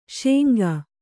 ♪ śengā